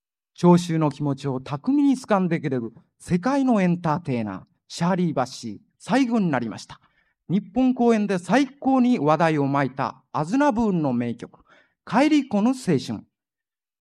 1976年2月、高田馬場BIGBOXで開催されたDJ大会。
▶ DJ音声⑥（3曲目の帰り来ぬ青春の紹介）
⑥DJの声-3曲目の紹介-6.mp3